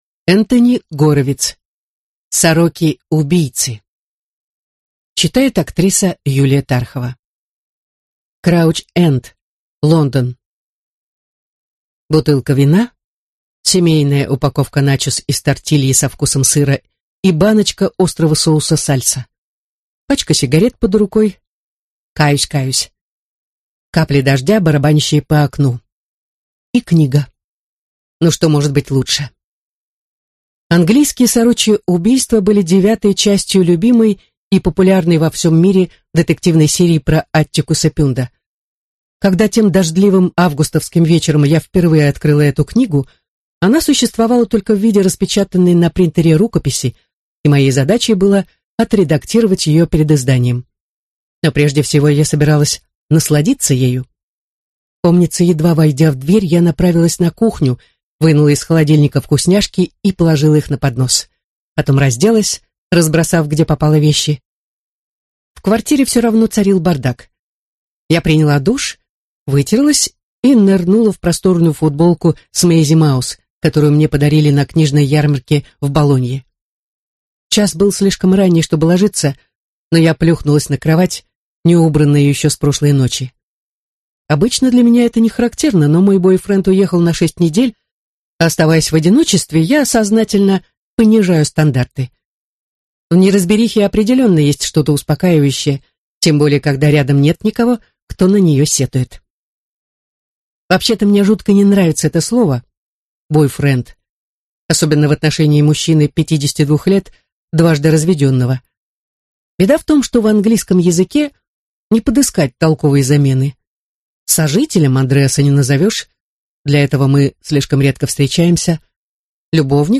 Аудиокнига Сороки-убийцы | Библиотека аудиокниг
Прослушать и бесплатно скачать фрагмент аудиокниги